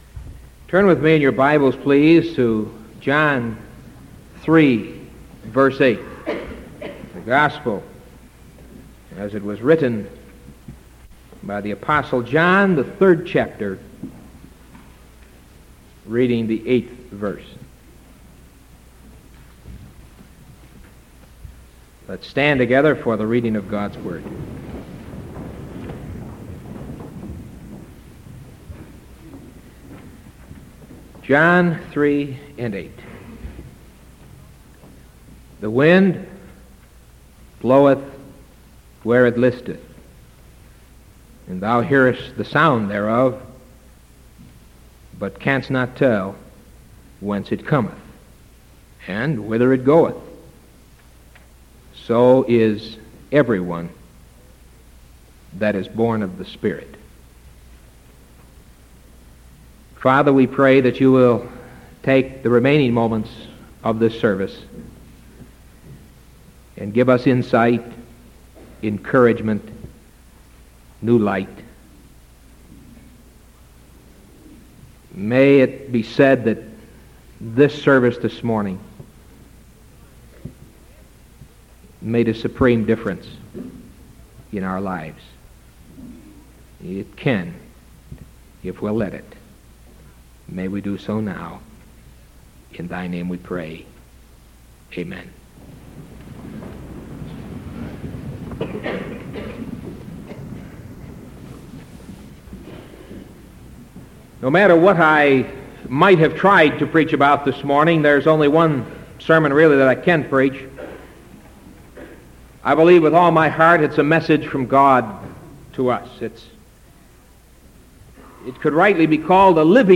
Sermon January 20th 1974 AM